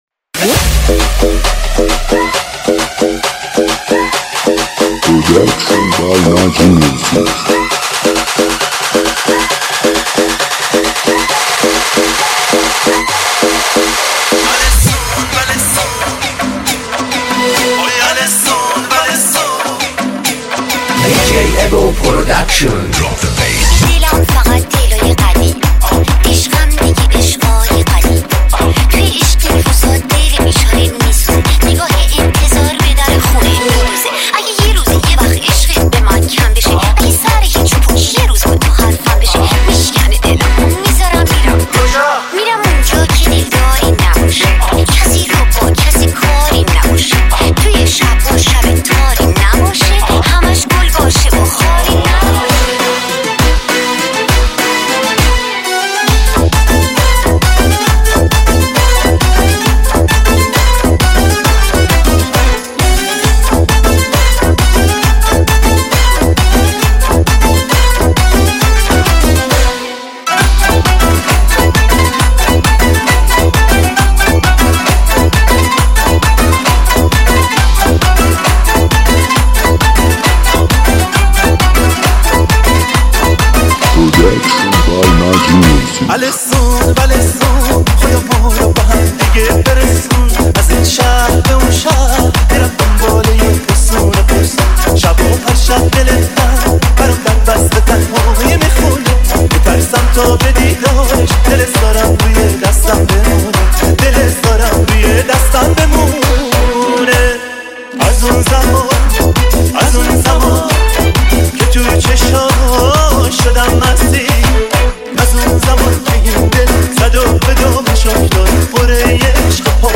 ریمیکس
ریمیکس شاد تریبال